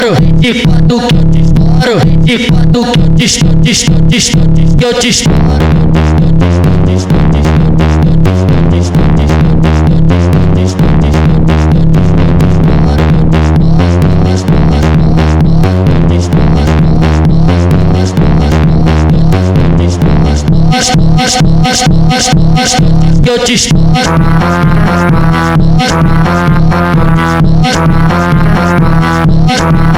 Жанр: R&B / Соул / Фанк